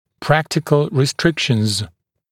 [‘præktɪkl rɪ’strɪkʃnz][‘прэктикл ри’стрикшнз]практические ограничения